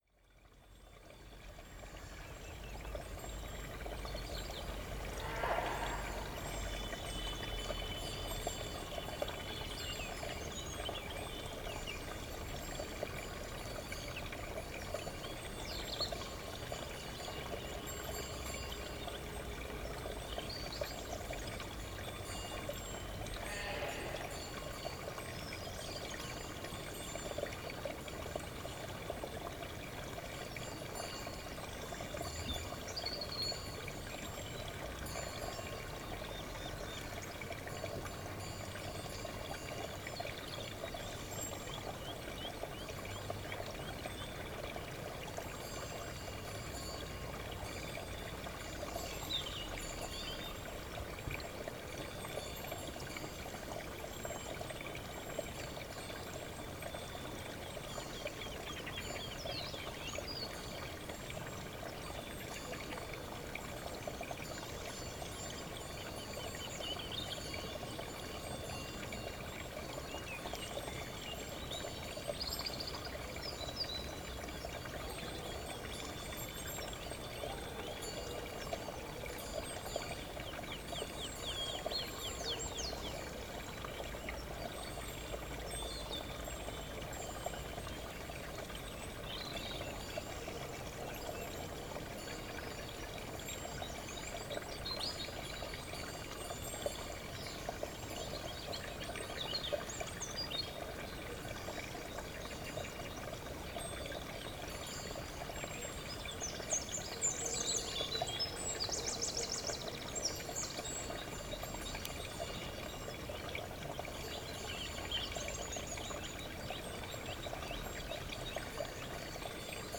O som dos chocalhos numa manhã de Inverno, um campo de pastoreio, um rego de água, uma avioneta, o sino da Igreja, um galo na distância. Paisagem sonora de ovelhas a pastar em Pousa Maria, Lordosa a 18 Fevereiro 2016.